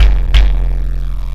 Kick Particle 03.wav